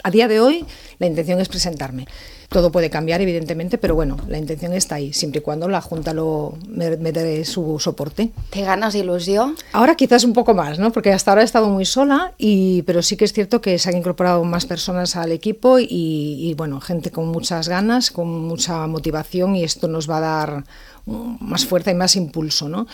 A l’ENTREVISTA POLÍTICA de RCT, Coronil ha qüestionat que el traspàs de competències i el model de gestió compartida Estat-Generalitat resolguin la crisi de Rodalies.